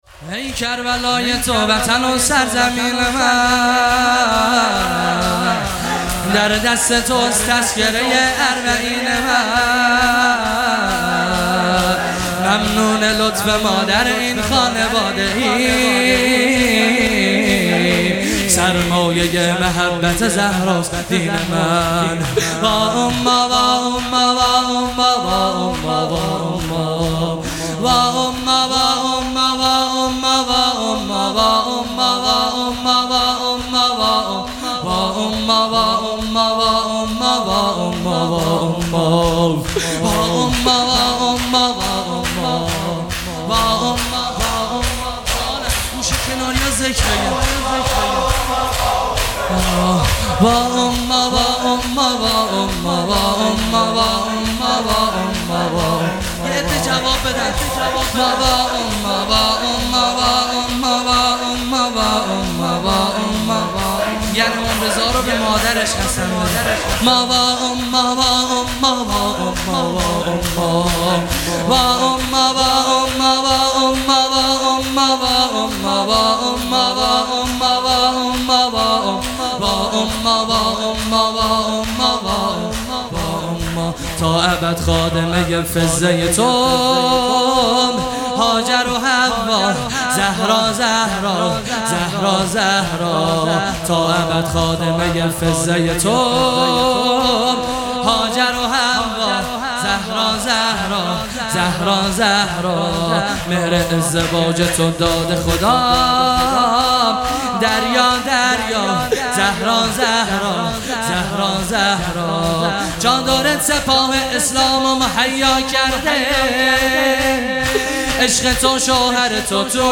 شور | ای کربلای تو وطن و سرزمین من | 2 تیر 1401
جلسۀ هفتگی | به مناسبت شهادت امام رضا(علیه السّلام) | پنجشنبه 2 تیر 1401